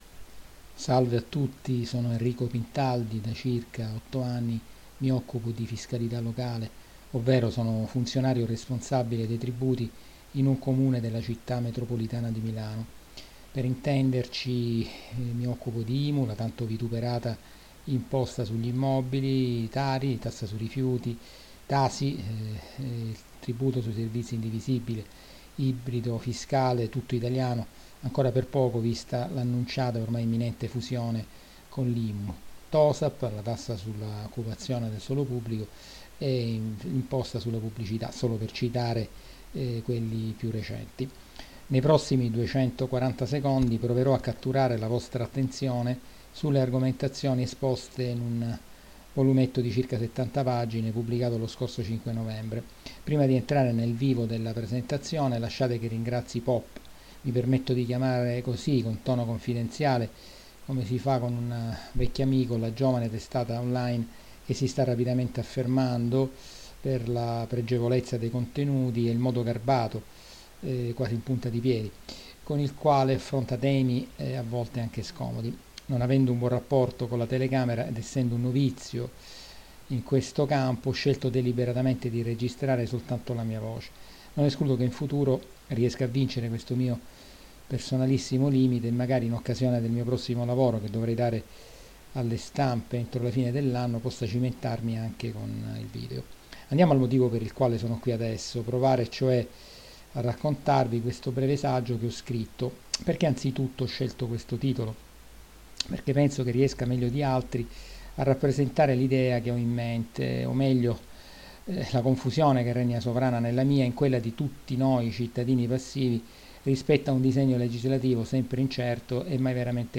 Qui di seguito vi proponiamo la presentazione audio del volumetto, registrata, in presa diretta per POP